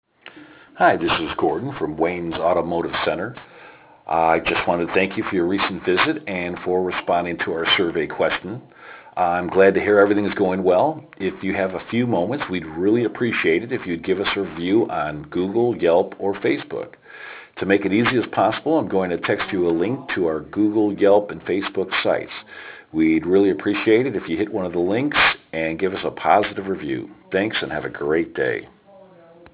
3. Send Customers a Voice Drop (Example Below).